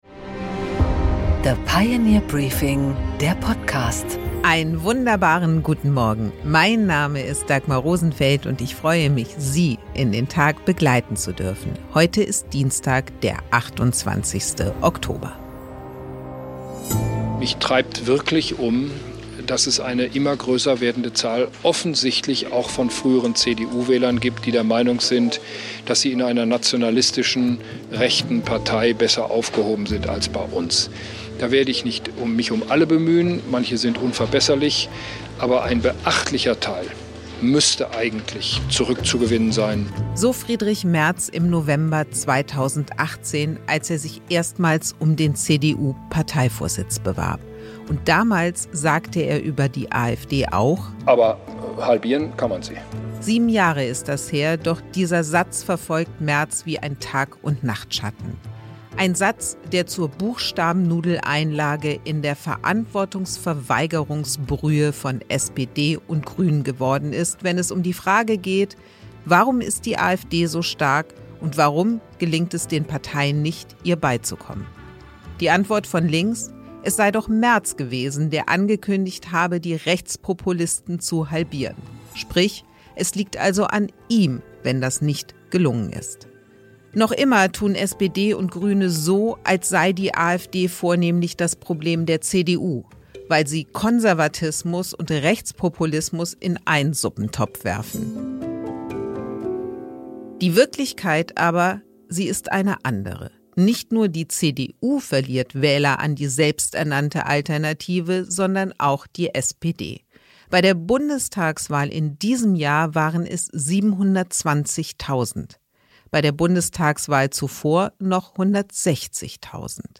Dagmar Rosenfeld präsentiert das Pioneer Briefing.
Im Gespräch: Dorothee Bär, MdB (CSU) und Bundesministerin für Forschung, Technologie und Raumfahrt, spricht mit Dagmar Rosenfeld über die Innovationslücke in Deutschland, Kernfusion – und die Frage, was sie von den Spar-Forderungen des Finanzministers an das schwarz-rote Kabinett hält.